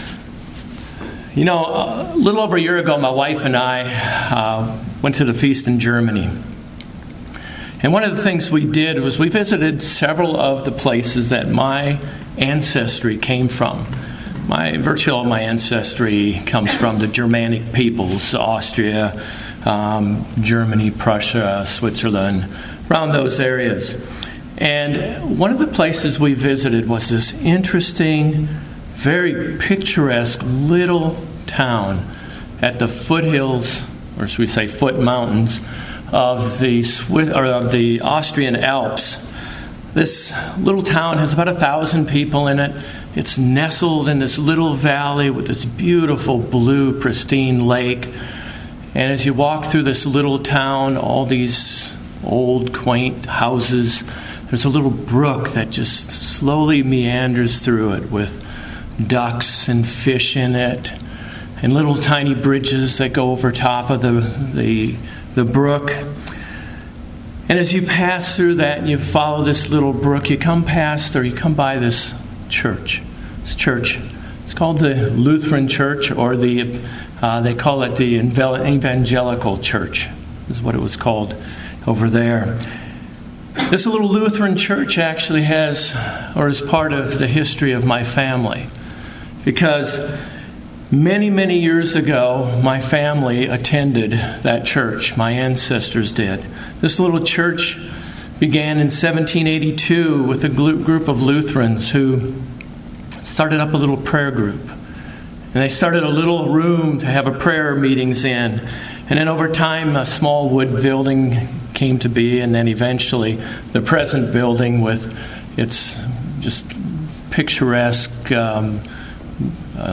Sermons
Given in Cincinnati North, OH